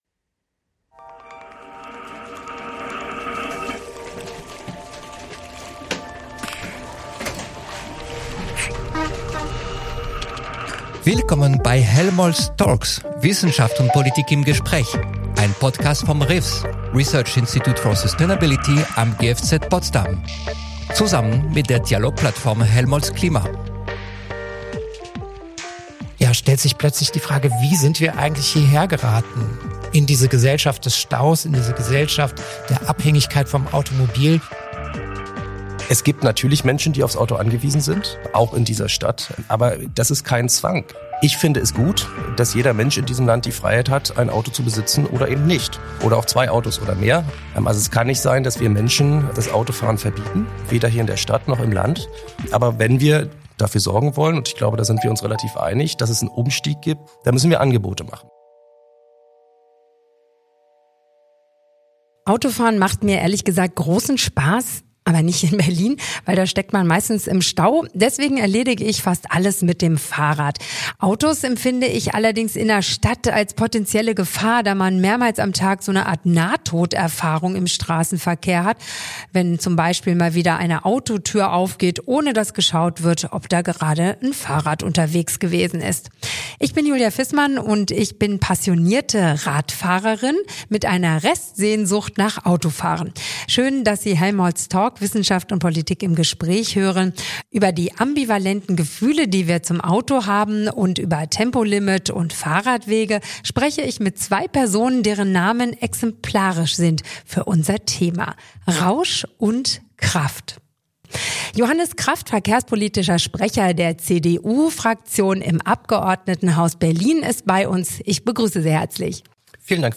Mit Johannes Kraft, verkehrspolitischem Sprecher der CDU im Abgeordnetenhaus von Berlin